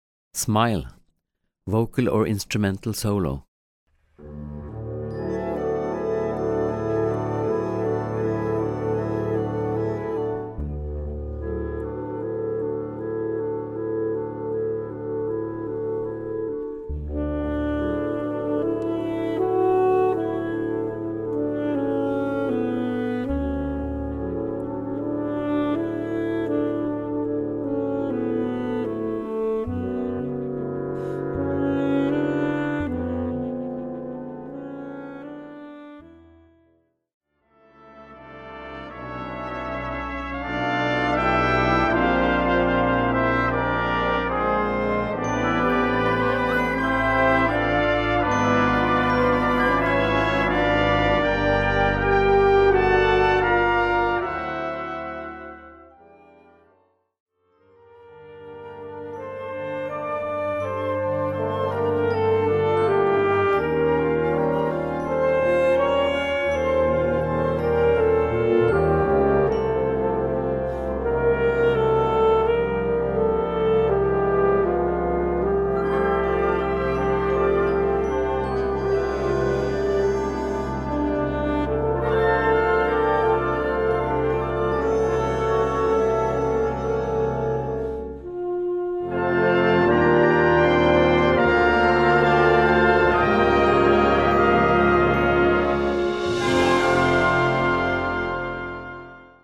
Gattung: Solo für Gesang, Altsaxophon oder Horn in F
Besetzung: Blasorchester